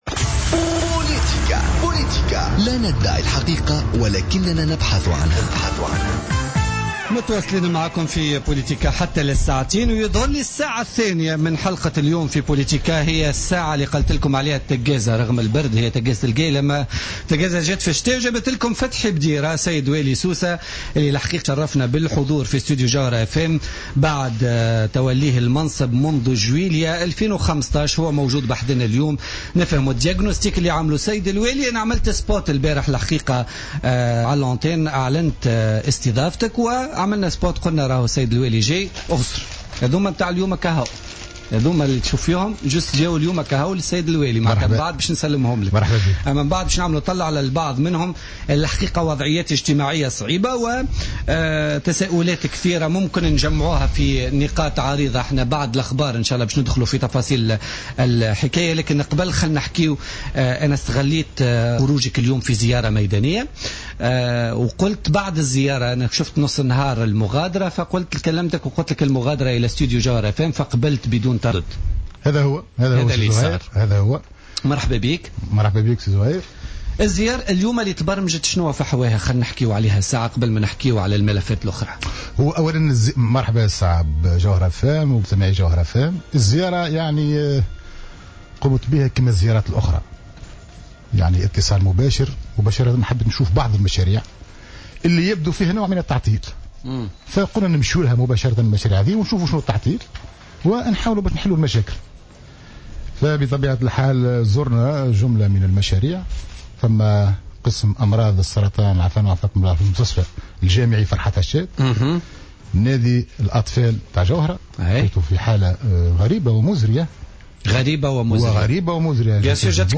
أكد والي سوسة فتحي بديرة ضيف بوليتيكا اليوم الثلاثاء 19 جانفي 2016 أن قام بزيارات ميدانية اليوم للإطلاع على بعض المشاريع المعطلة و الاستفسار حول أسباب تعطلها.